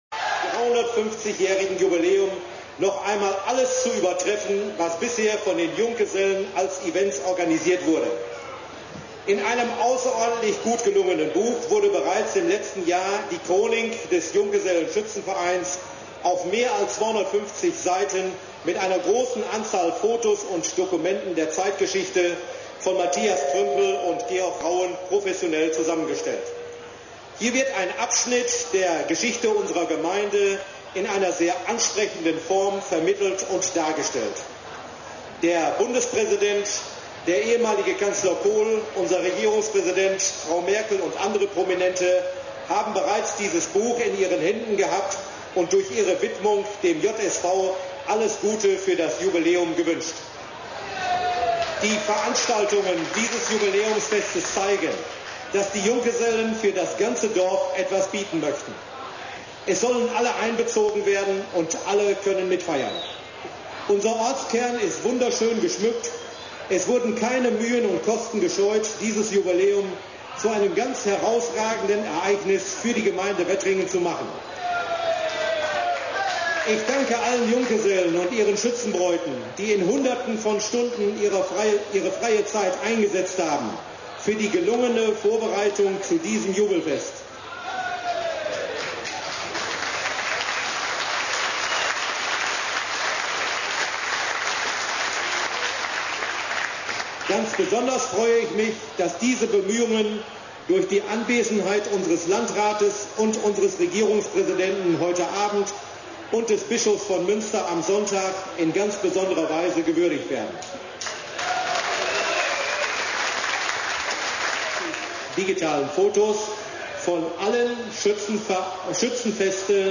Rede des Bürgermeisters Rauen